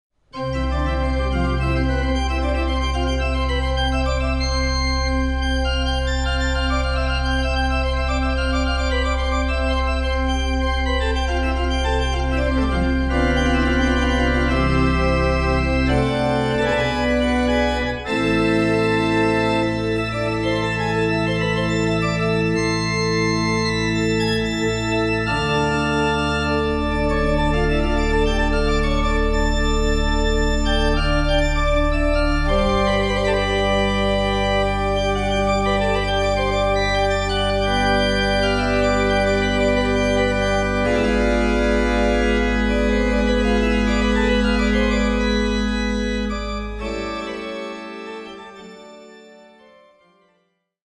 CD Nr. 16 « Schweizerische Hausorgeln »